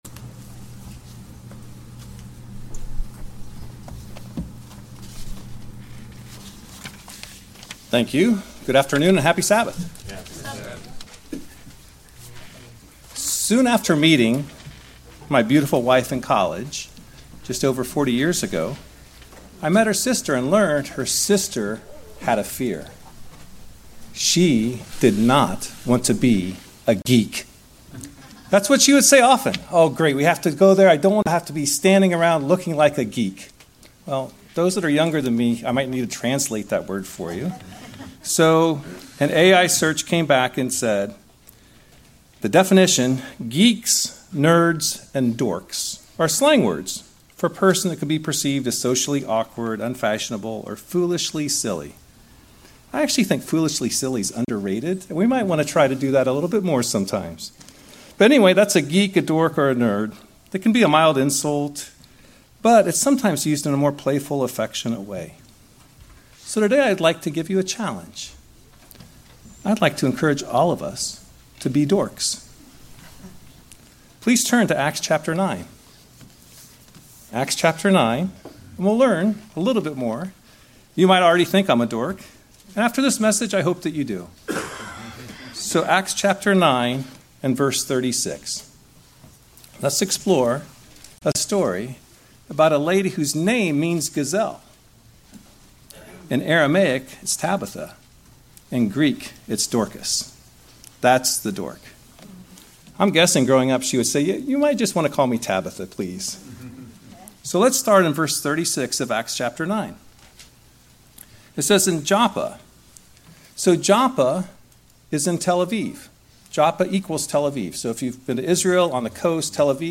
Given in Cincinnati East, OH